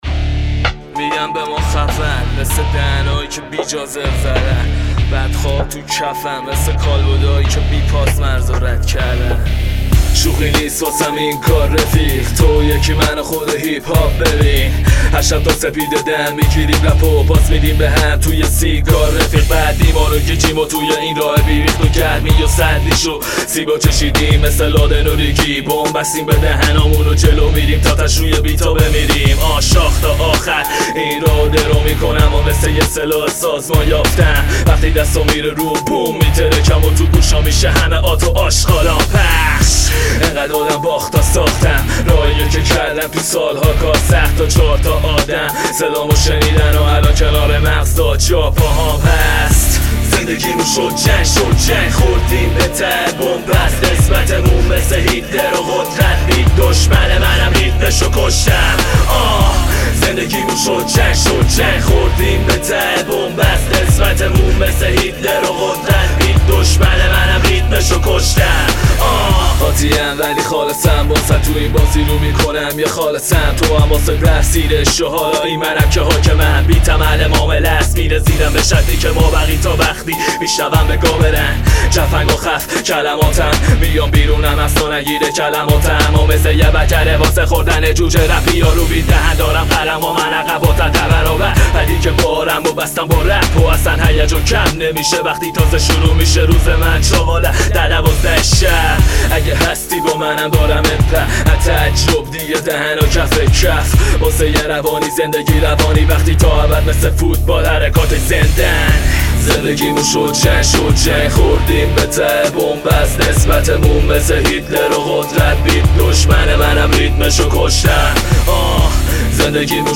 آهنگ رپ جدید
دانلود آهنگ رپ